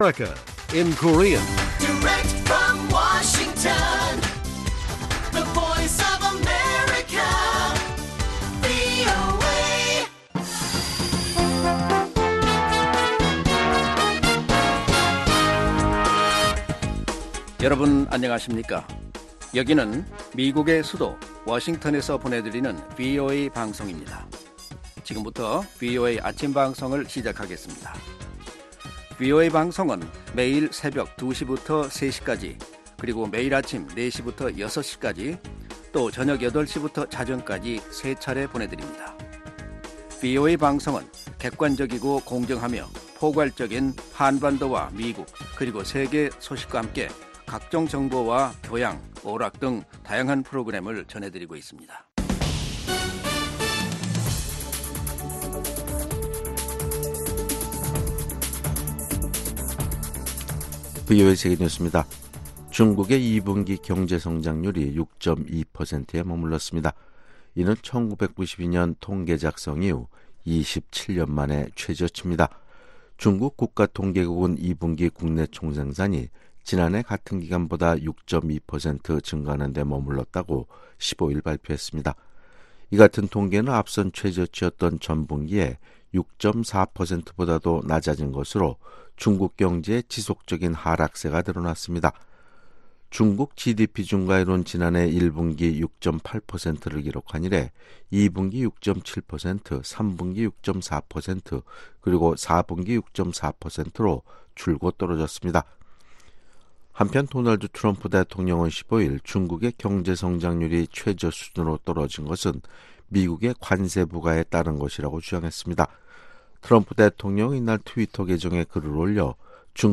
세계 뉴스와 함께 미국의 모든 것을 소개하는 '생방송 여기는 워싱턴입니다', 2019년 7월 16일 아침 방송입니다. ‘지구촌 오늘’은 제러미 헌트 영국 외무 장관이 위기를 맞은 ‘이란 핵 합의’를 구할 수 있는 희망은 여전히 있다고 밝혔다는 소식, ‘아메리카 나우’에서는 중미 나라 국민들이 미국에 망명을 신청하는 것을 크게 제한하는 규정이 발효된다는 이야기를 소개합니다. '구석구석 미국 이야기'에서는 뉴욕 맨해튼에 나타난 고래 떼 이야기를 소개합니다.